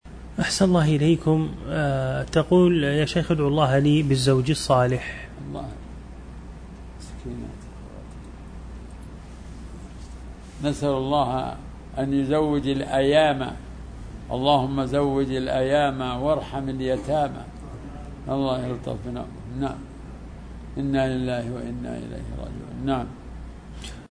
فتاة تطلب من الشيخ البراك الدعاء لها بالزوج الصالح